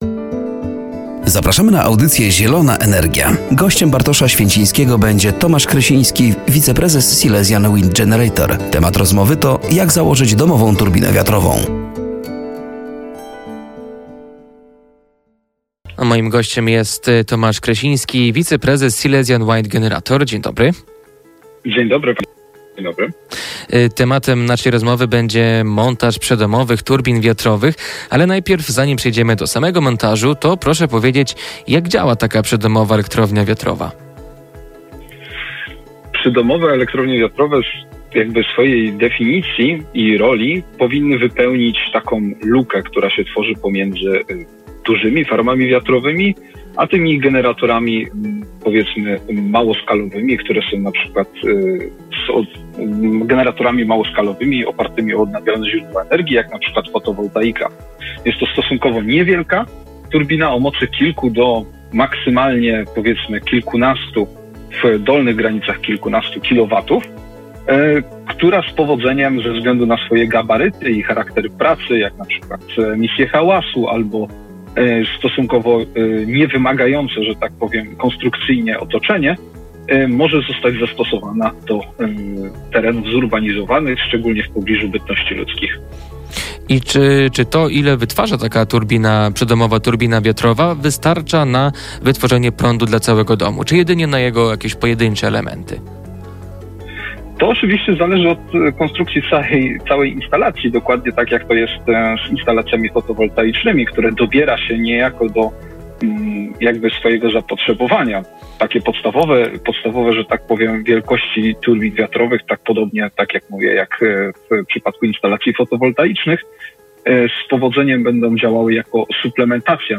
“Zielona Energia” w piątek o g. 10.15 na antenie Radia Nadzieja.